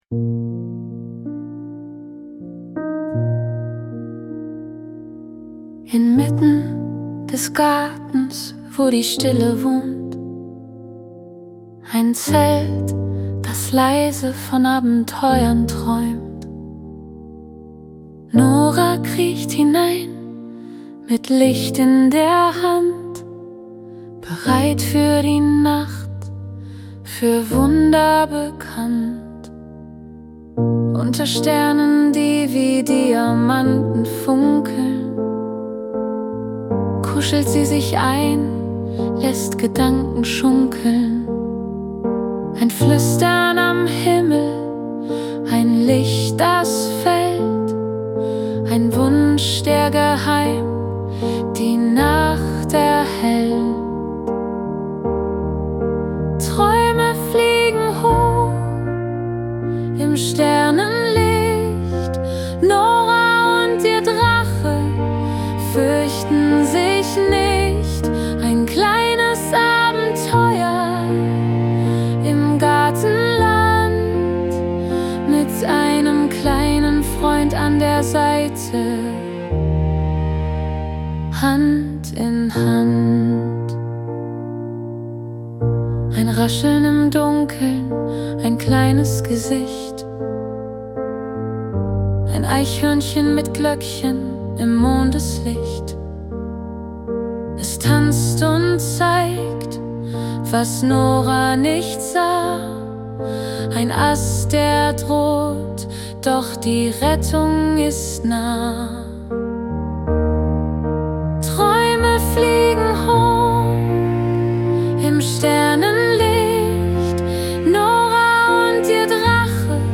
Das Schlaflied zur Geschichte
🎵 Musik und Gesang: Suno | AI Music